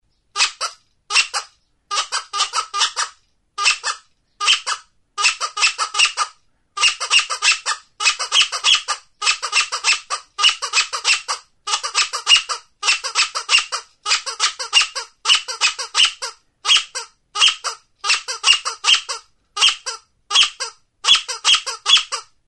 Instrumentos de músicaTURUTA (bizikletarako); BOCINA
Aerófonos -> Lengüetas -> Simple (clarinete)
Grabado con este instrumento.
Bizikletarako klaxon gisako mihi bakuneko plastikozko turuta da.